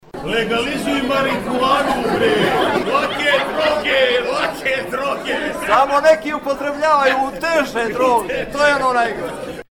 Potpredsjednik parlamenta Branko Radulović je kroz skupštinske hodnike prozivao lidera Liberalne partije Andriju Popovića.